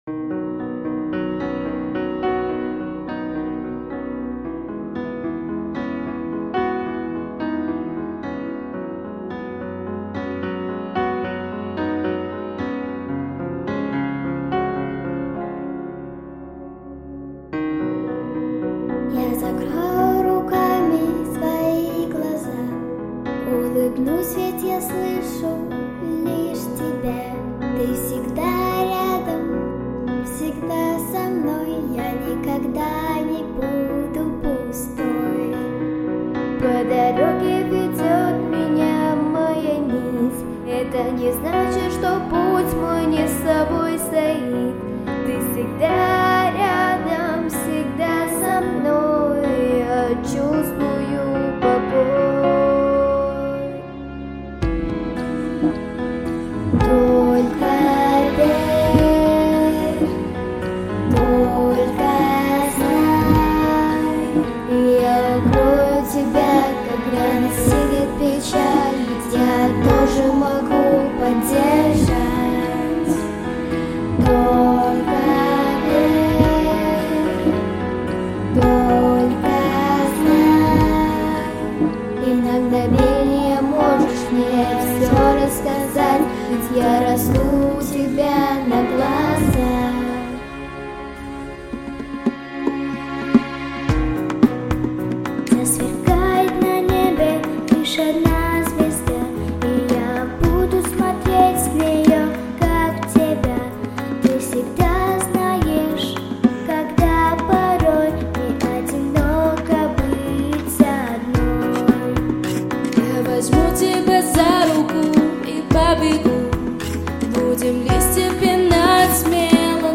🎶 Детские песни / Песни про маму